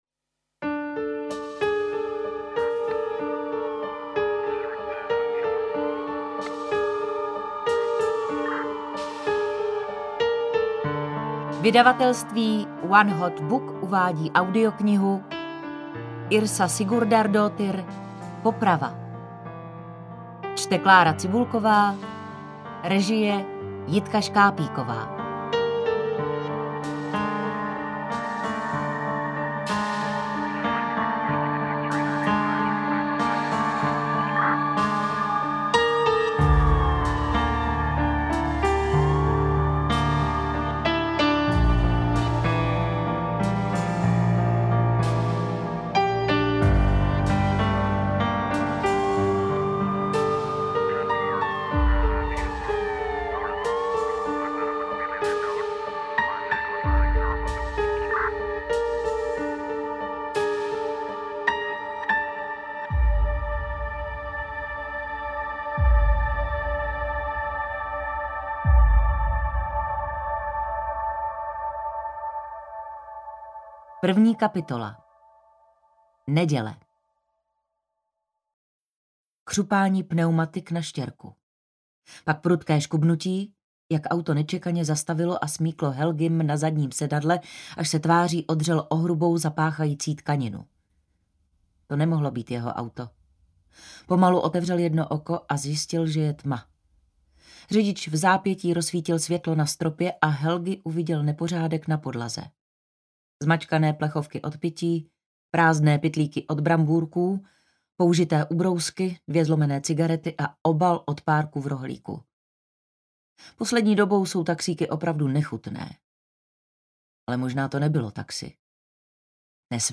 Interpret:  Klára Cibulková
AudioKniha ke stažení, 34 x mp3, délka 11 hod. 41 min., velikost 628,0 MB, česky